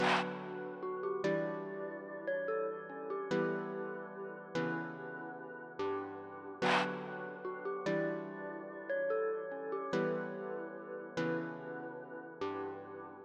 描述：1循环4你:) 145bpm
Tag: 环境 循环 电子